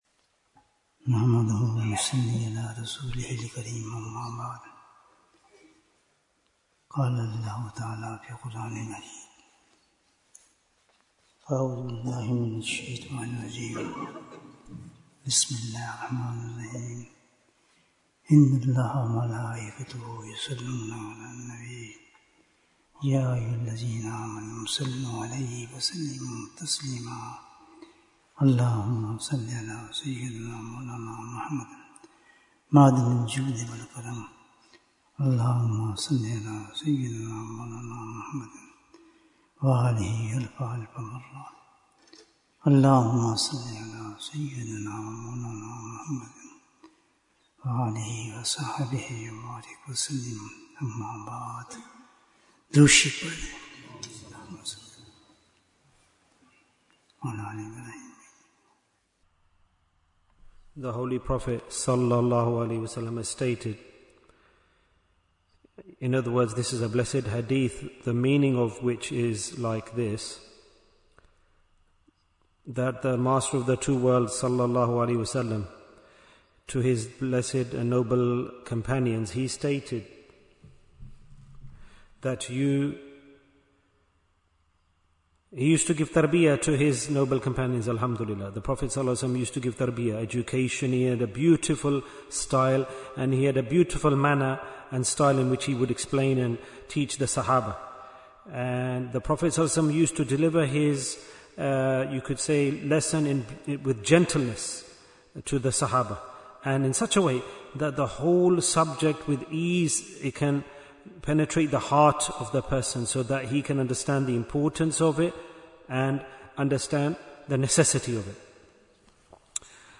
Majlis-e-Dhikr in Bradford Bayan, 68 minutes19th October, 2025